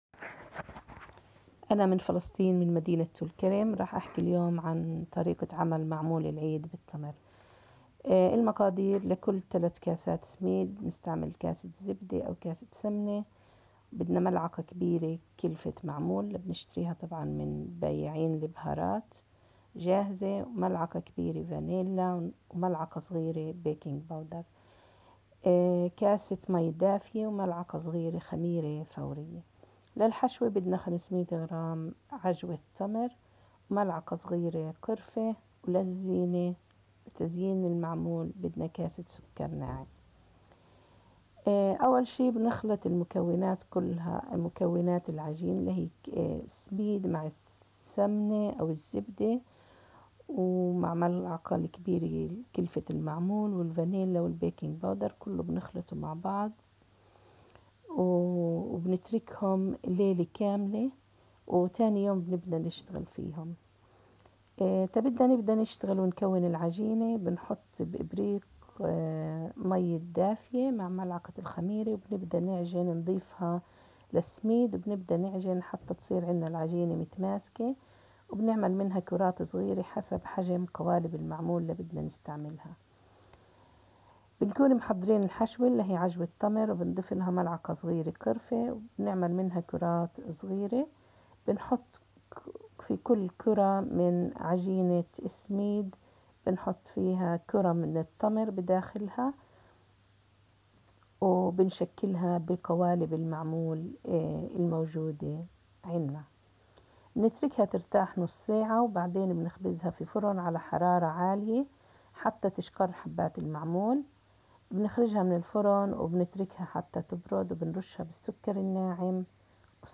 PalestinianRecipe-1.m4a